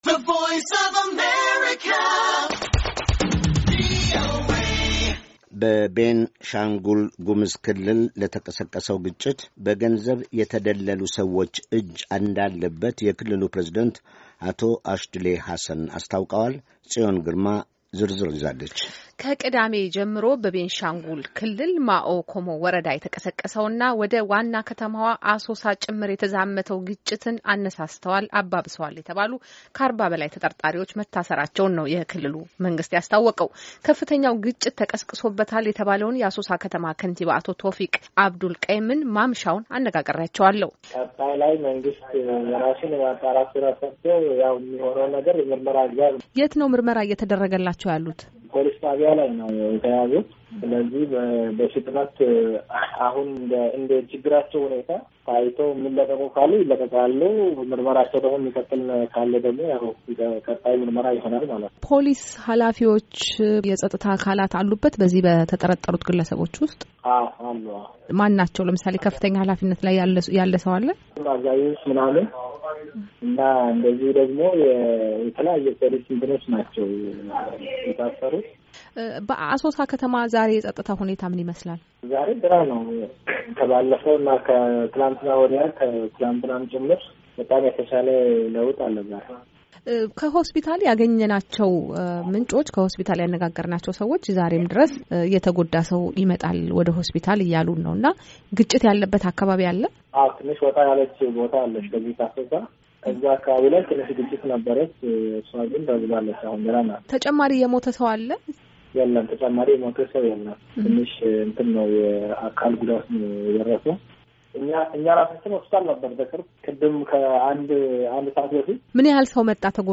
ከአሶሳ ከተማ ከንቲባ አቶ ቶፊቅ አብዱልቀይም ጋራ ያደረገችውን አጭር ቆይታ